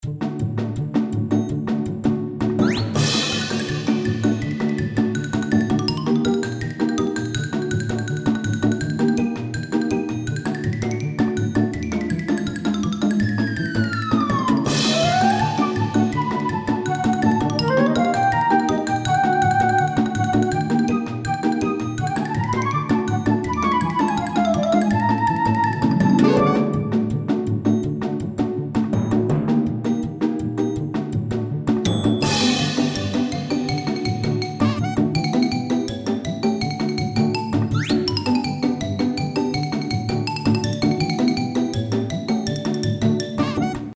ファンタジー系フリーBGM｜ゲーム・動画・TRPGなどに！
コミカルでスピーディな感じ。